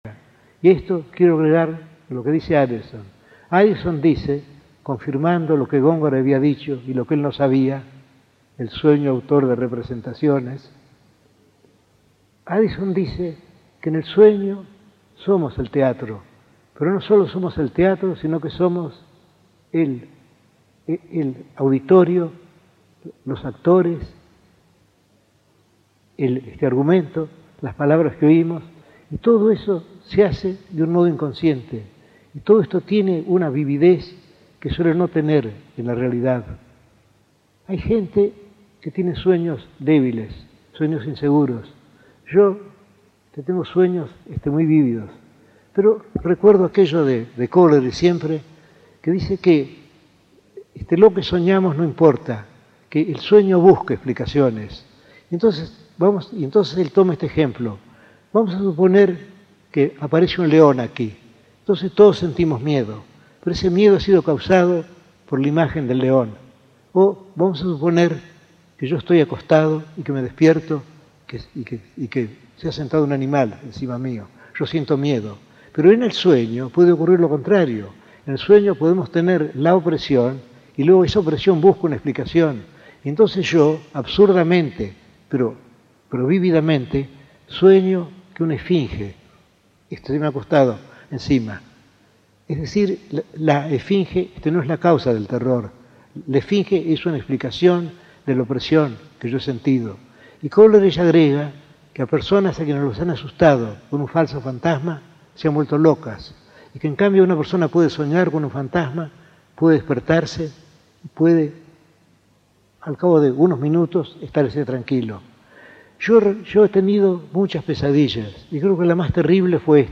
Conferencia en el Teatro Coliseo de BBAA, el 15 de junio de 1977